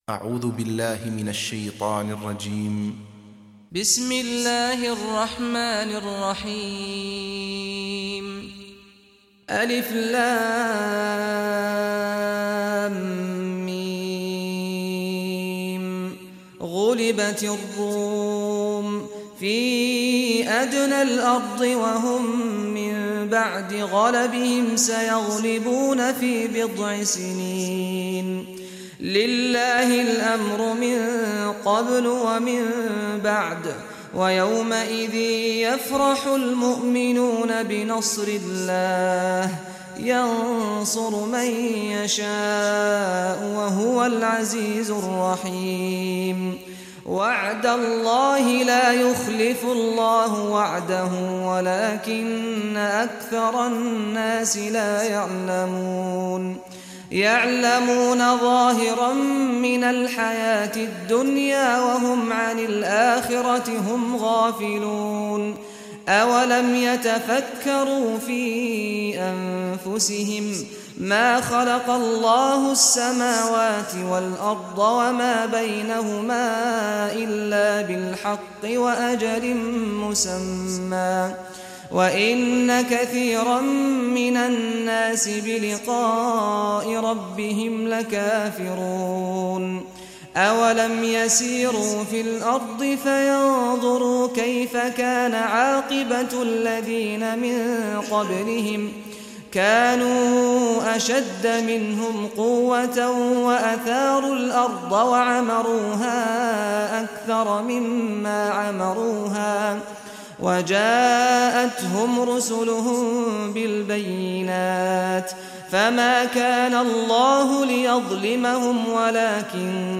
Surah Rum Recitation by Sheikh Saad al Ghamdi
Surah Rum, listen online mp3 tilawat / recitation in Arabic in the beautiful voice of Sheikh Saad al Ghamdi.
30-surah-rum.mp3